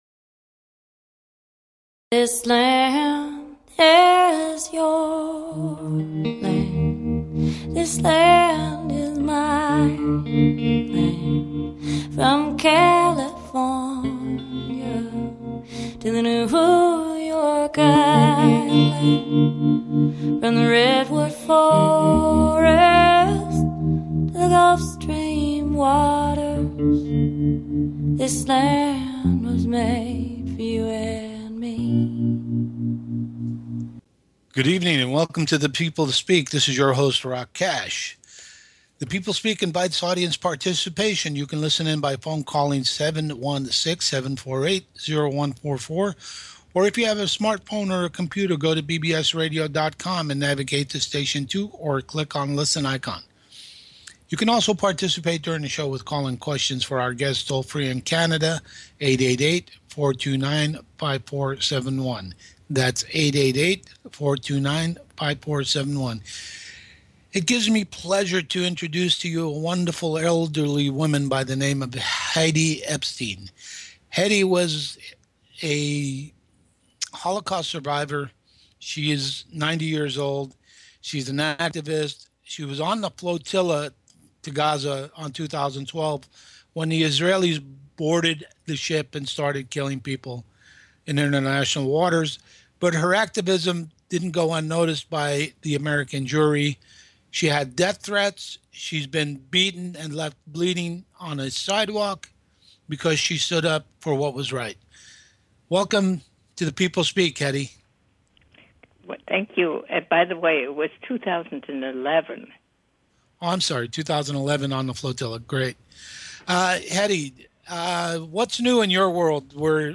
Talk Show Episode, Audio Podcast, The People Speak and Guests